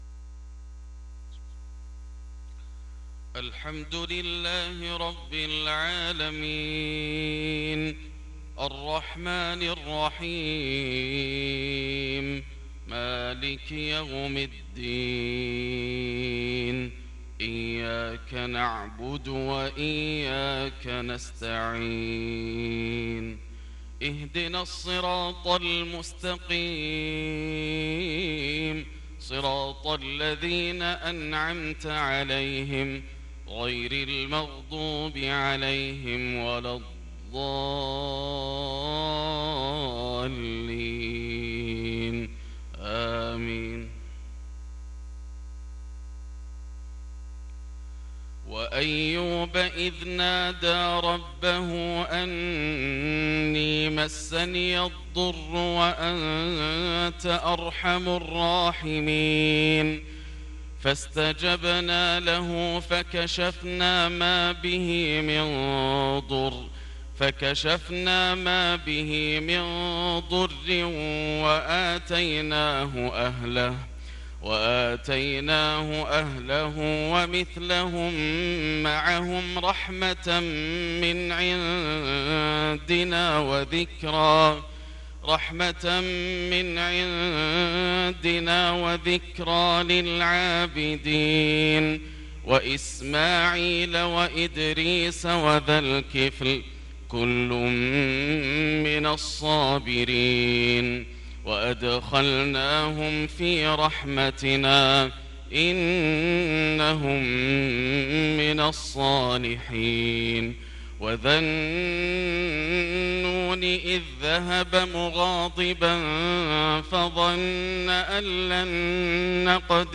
صلاة الفجر للقارئ ياسر الدوسري 5 ربيع الأول 1444 هـ
تِلَاوَات الْحَرَمَيْن .